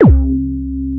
PROBASSHC2-L.wav